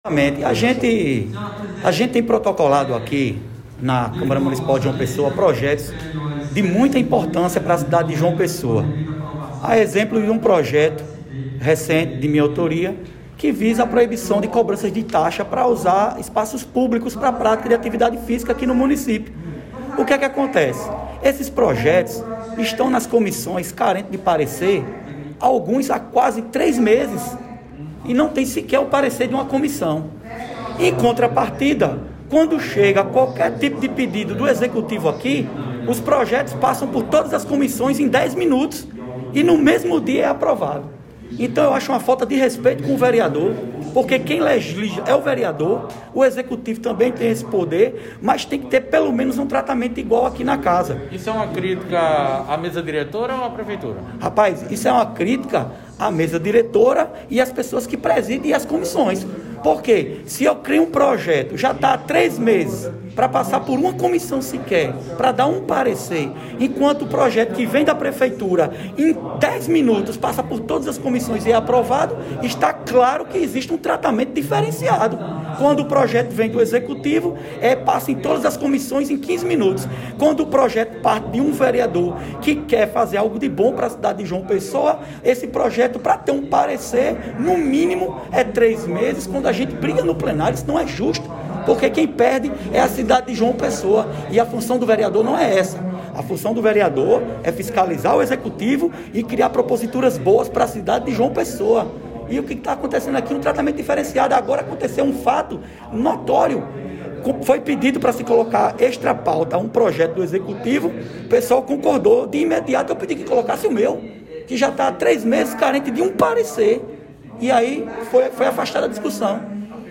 Abaixo a fala do vereador Junio Leandro.